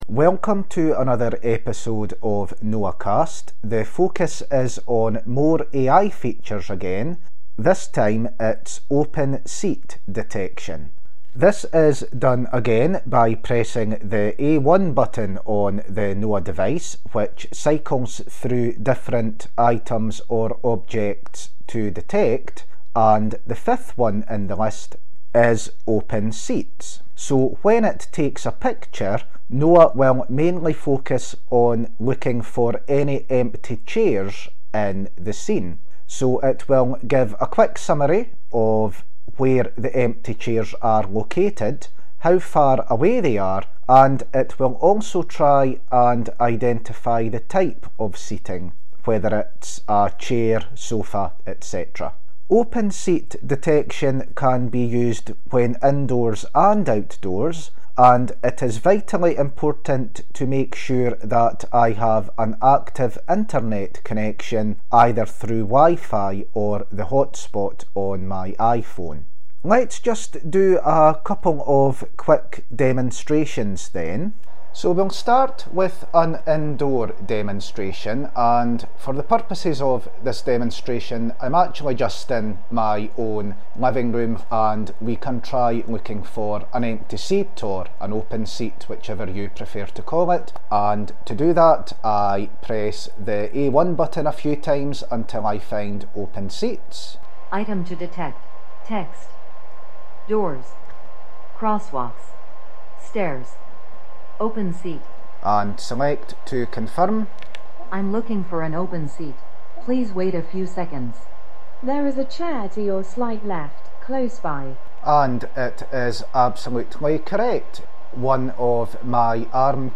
A quick demonstration of using NOA to detect empty seats in both indoor and outdoor situations.